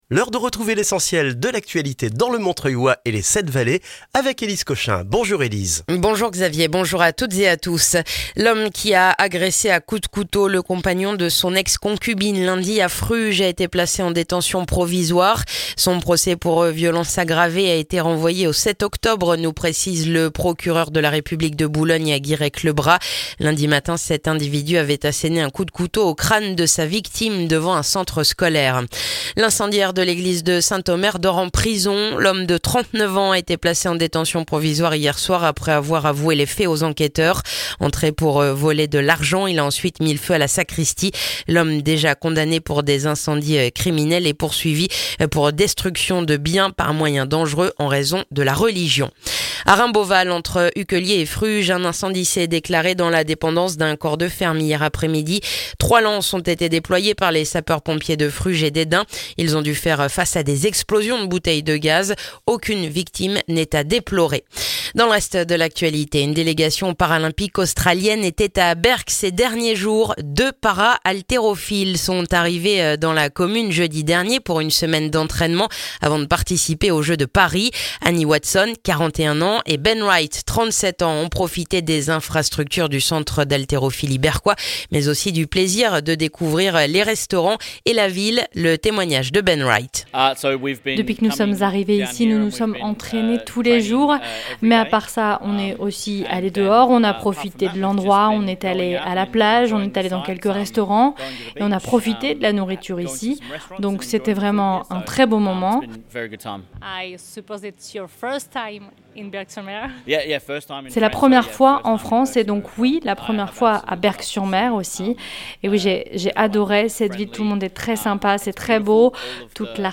Le journal du jeudi 5 septembre dans le montreuillois et les 7 Vallées